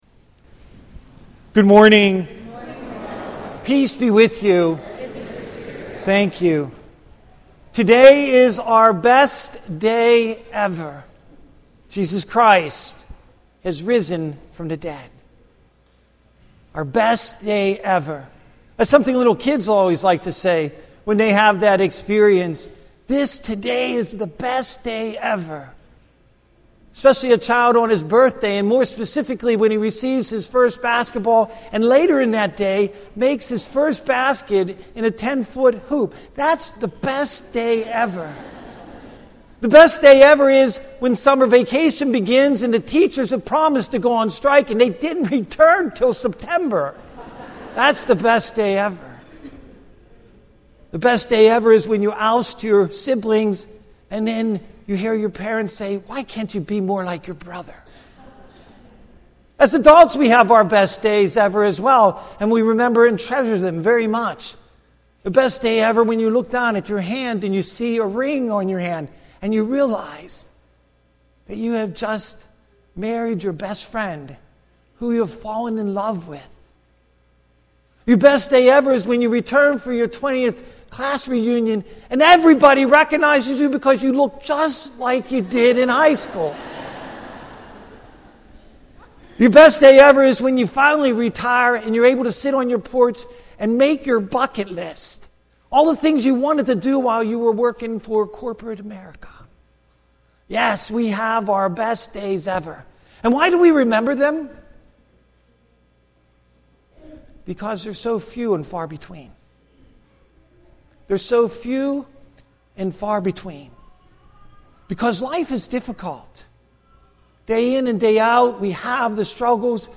Weekly Homilies